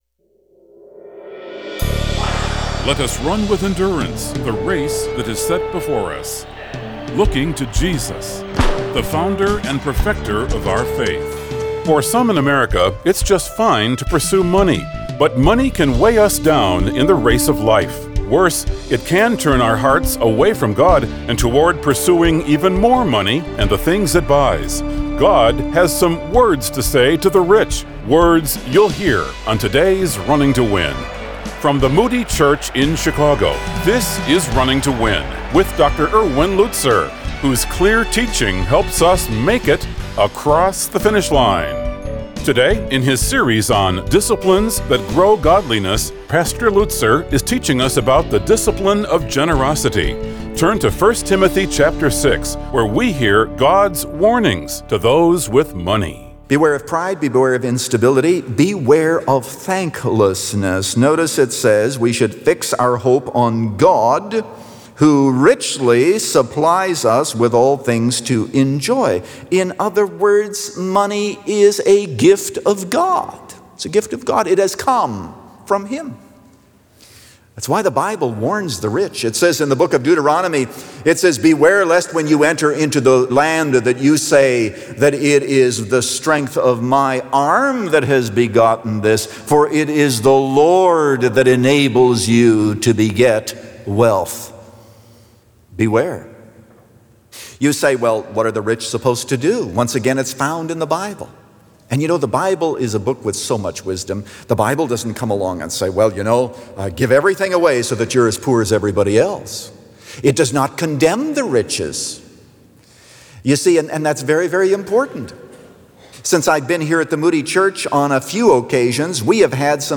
The Discipline Of Generosity – Part 3 of 4 | Radio Programs | Running to Win - 15 Minutes | Moody Church Media